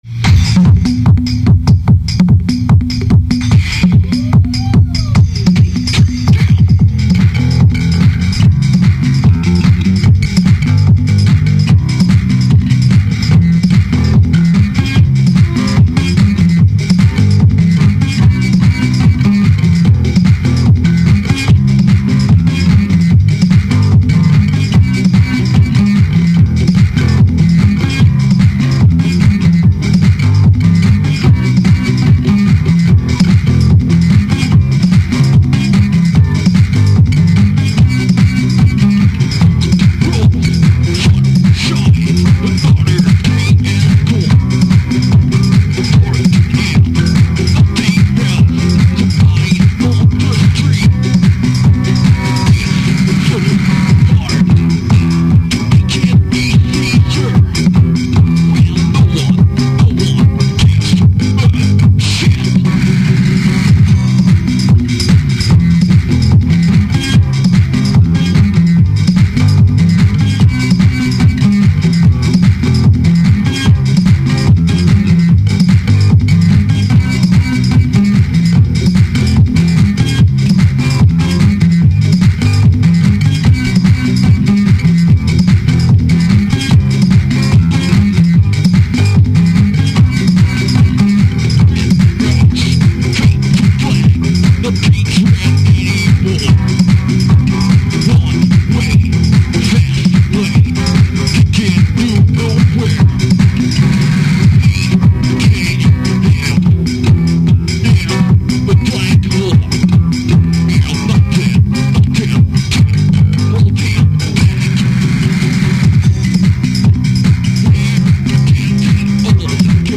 version 'Dance'